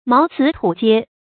茅茨土階 注音： ㄇㄠˊ ㄘㄧˊ ㄊㄨˇ ㄐㄧㄝ 讀音讀法： 意思解釋： 茅草蓋的屋頂，泥土砌的臺階。